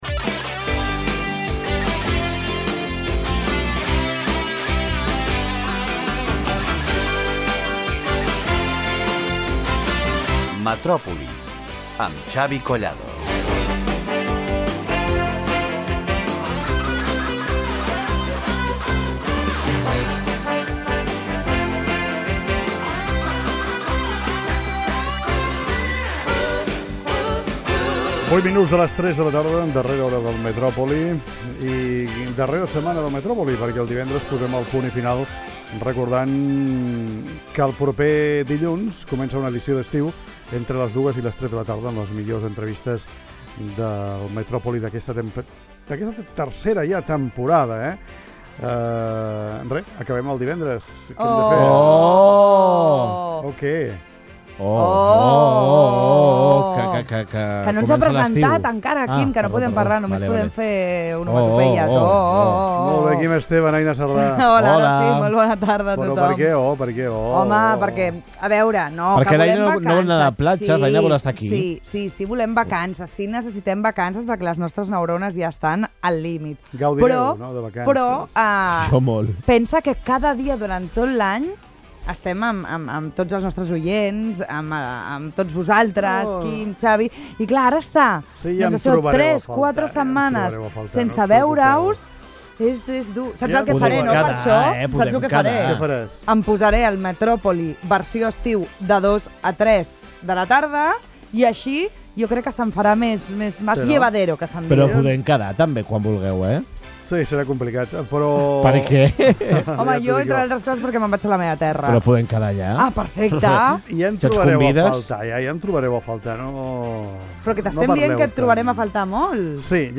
Indicatiu del programa
Entreteniment